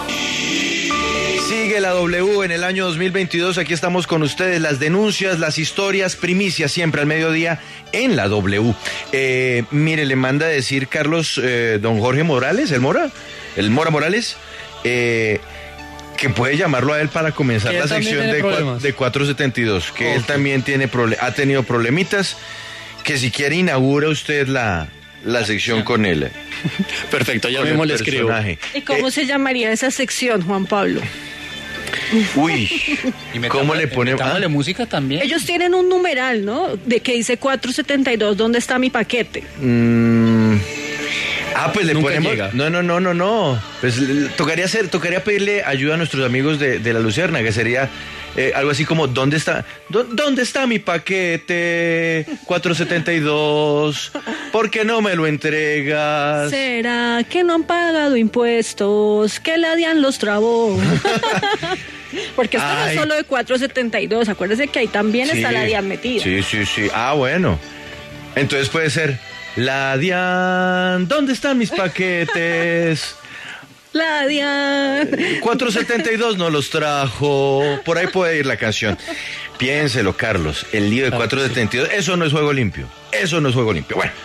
villancico